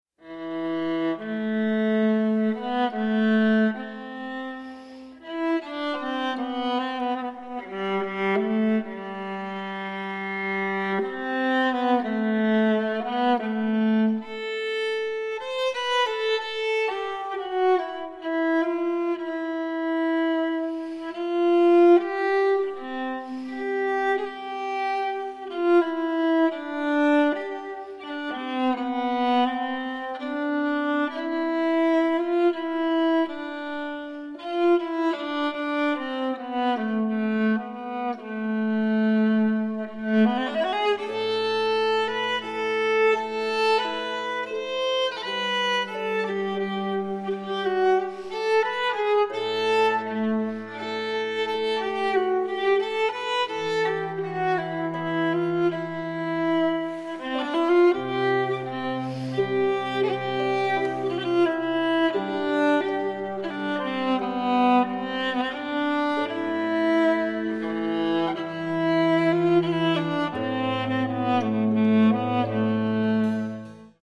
• Genres: Early Music, Opera